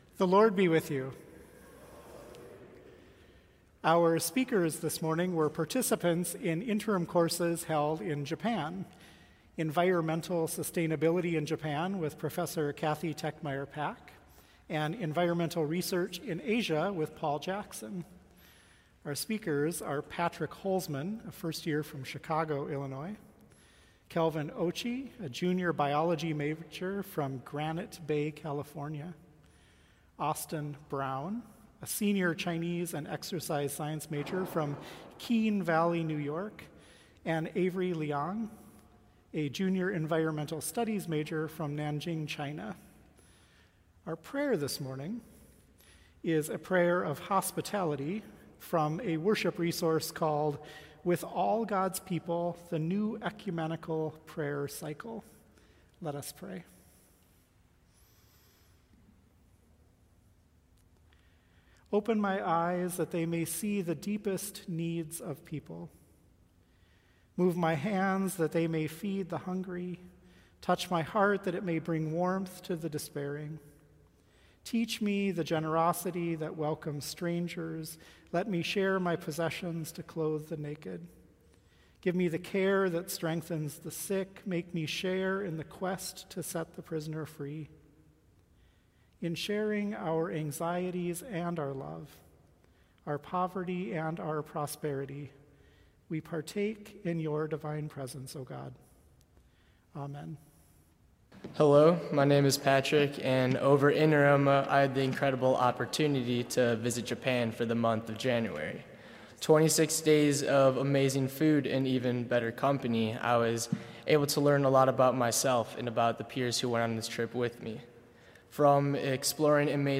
Students from the Environmental Sustainability in Japan Interim Course
Chapel Service